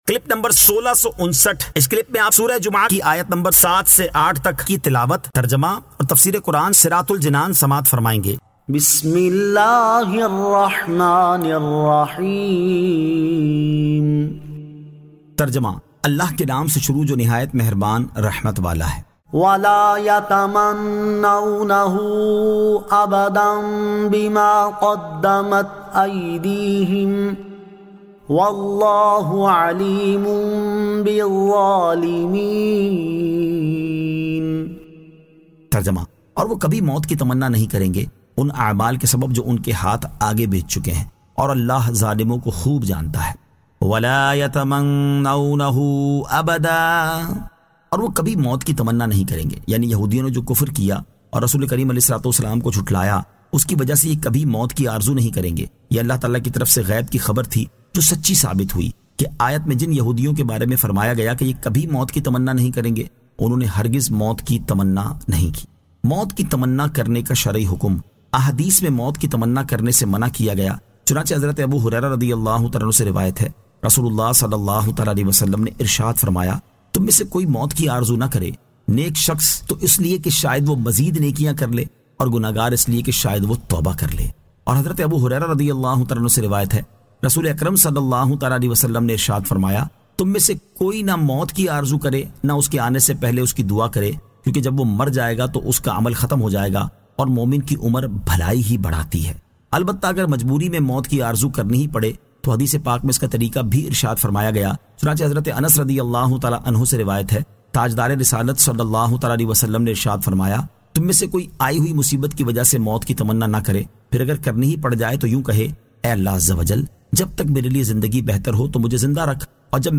Surah Al-Jumu'ah 07 To 08 Tilawat , Tarjama , Tafseer